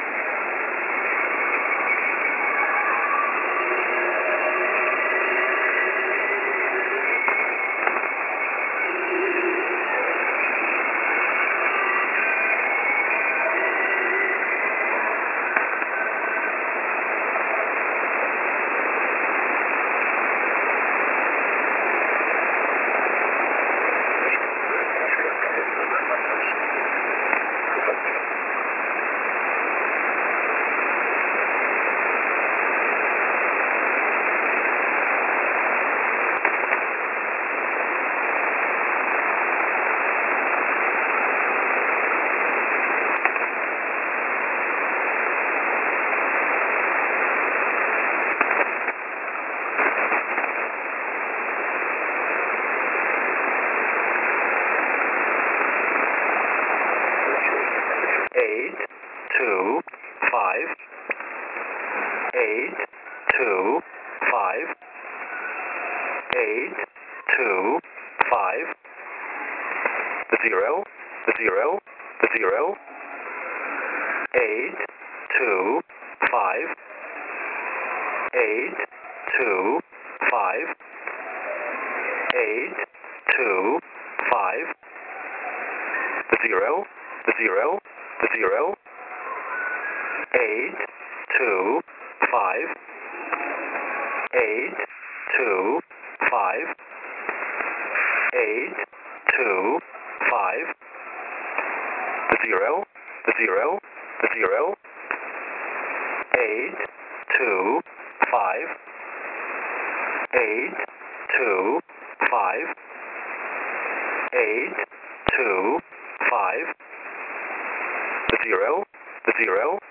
Mode: AM